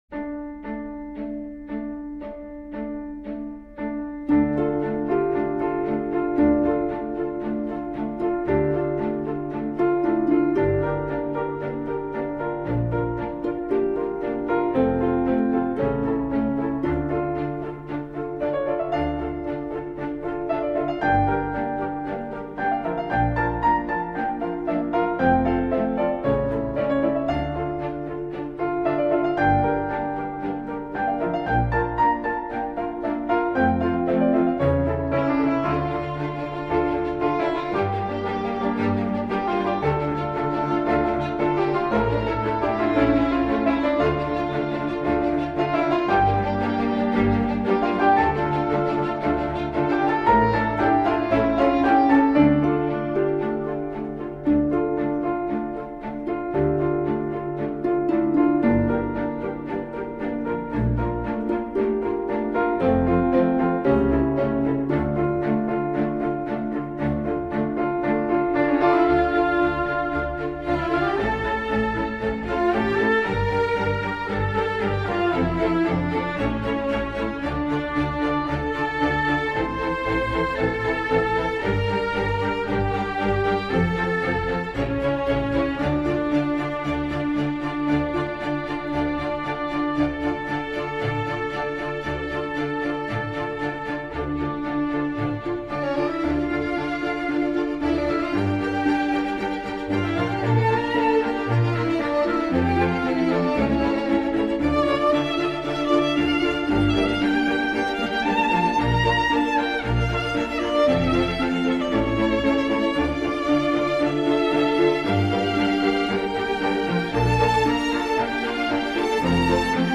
Ça débute en mode sicilien, trompette solo…